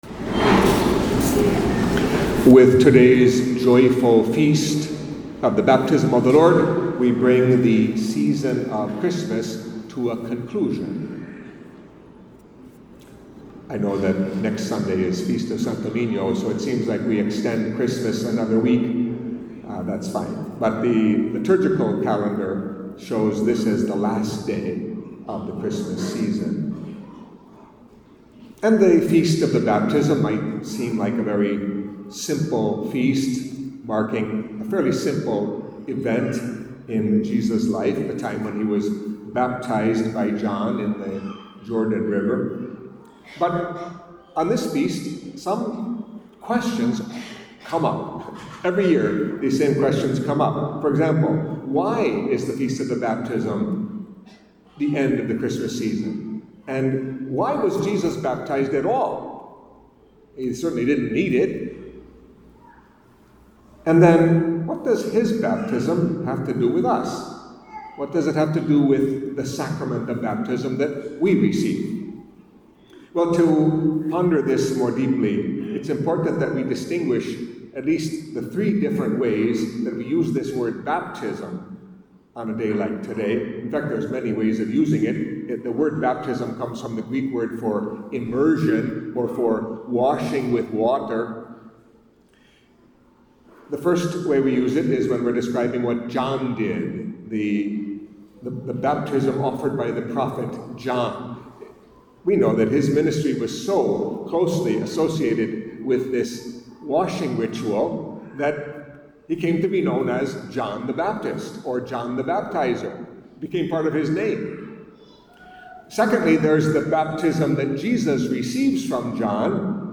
Catholic Mass homily for the Feast of the Baptism of the Lord